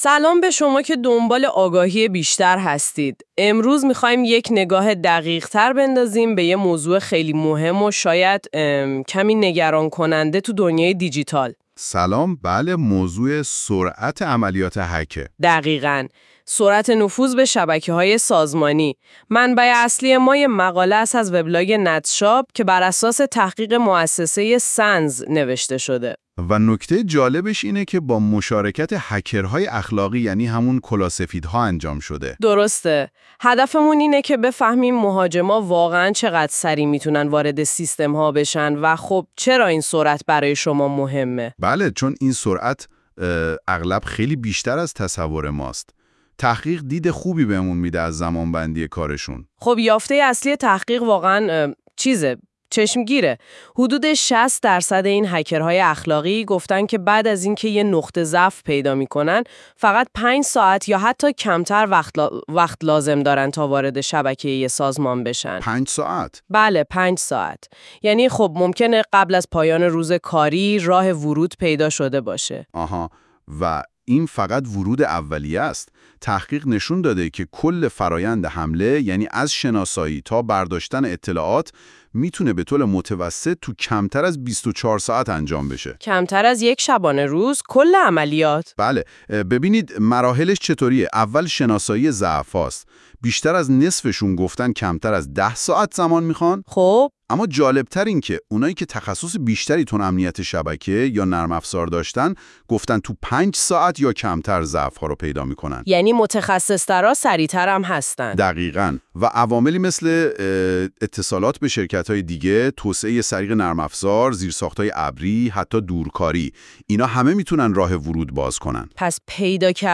نسخه صوتی مقاله زیر